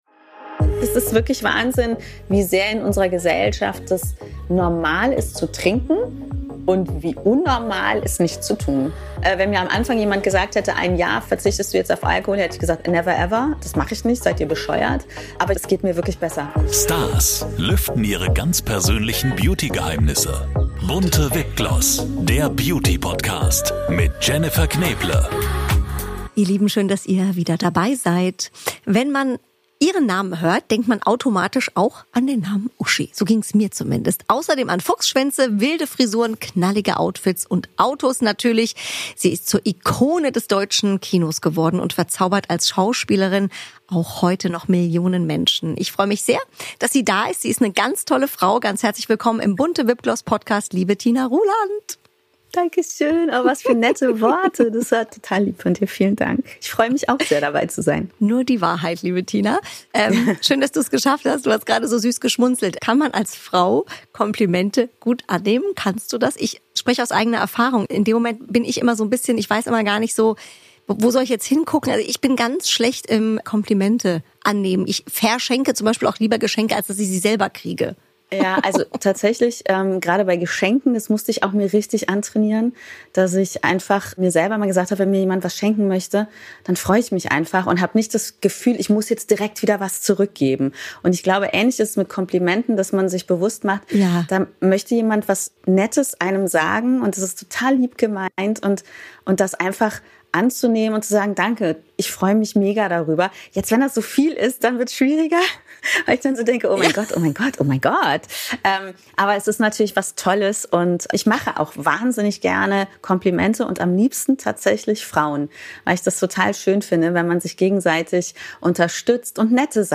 Ein Gespräch voller Witz, Tiefe und Ehrlichkeit – Tina Ruland bei BUNTE VIP GLOSS.